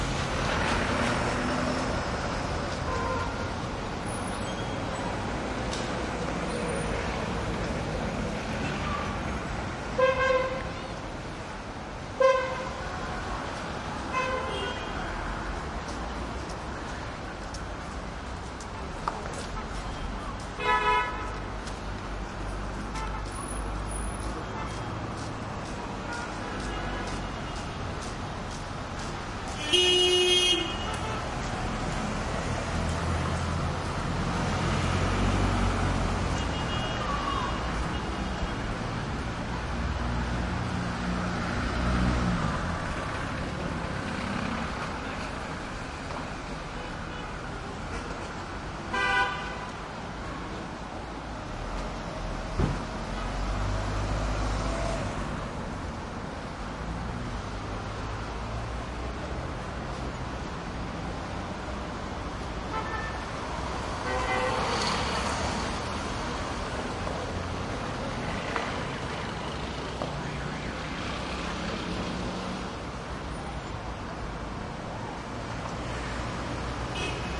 加沙 " 交通繁忙的中东地区 繁忙的林荫道上的悸动，响亮的喇叭声+马匹小跑通过中间的加沙地带 2016年
描述：交通繁忙的中东繁忙的林荫大道嘶哑的运动响亮的喇叭鸣笛+马小跑通过中加沙2016.wav
标签： 交通 繁忙 中东 喇叭 honks 林荫大道 城市
声道立体声